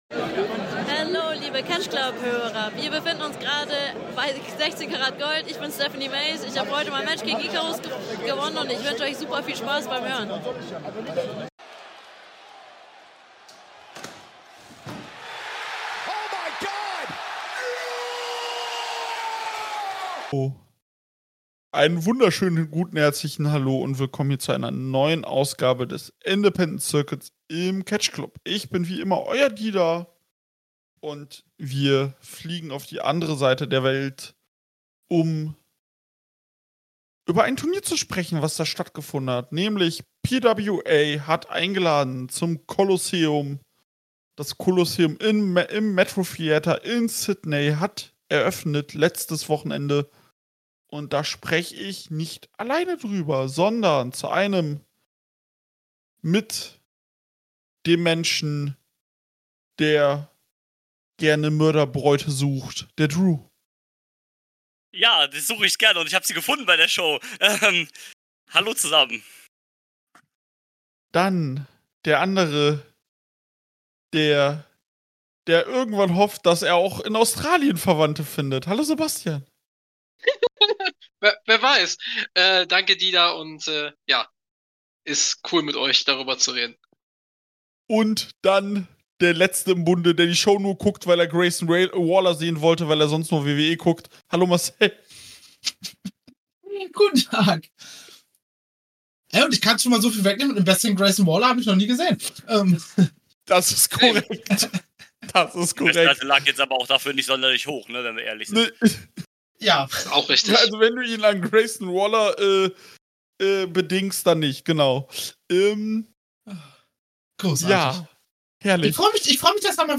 Endlich war es soweit, das Colosseum 2024 stand auf dem Programm. In der großen 4er Runde gehen die Jungs auf alle Matches des gesamten Wochenende ein und stellen sich die Frage, warum sind an diesem Wochenende Australier gegen Ihre Freunde geturnt?